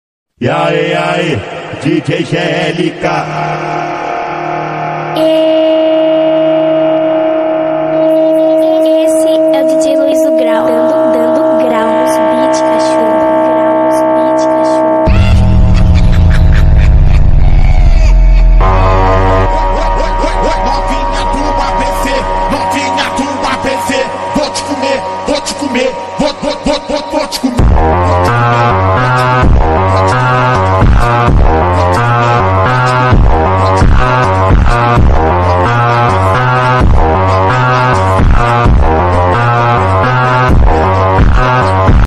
وينكم جماعة الببجي 😈🎧موسيقة حماسية sound effects free download